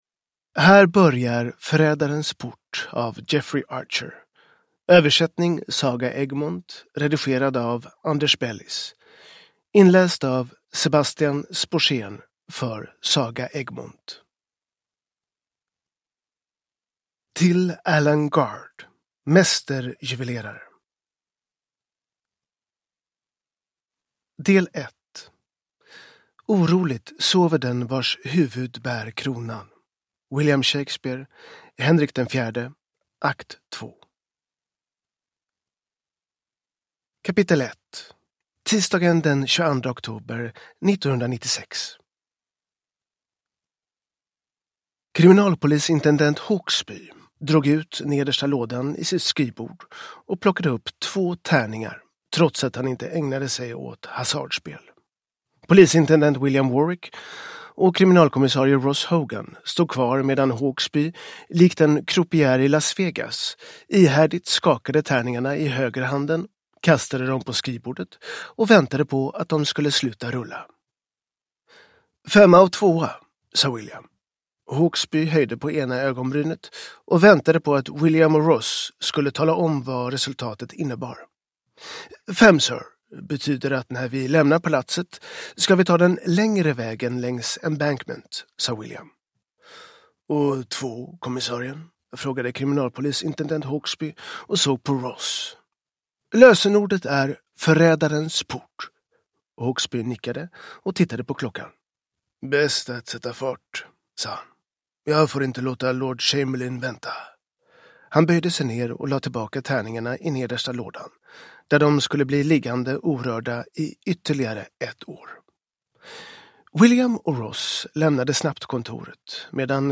Förrädarens port – Ljudbok